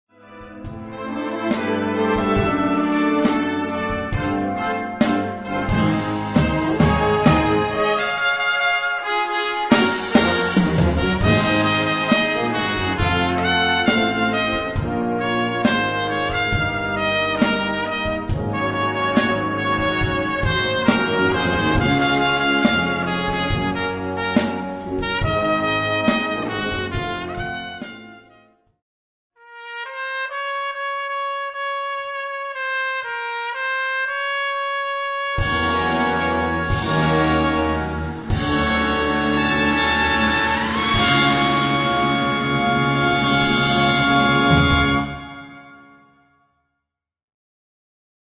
Instrumentation: Trompete & Blasorchester
Sparte: Popularmusik
für Solotrompete und Blasorchester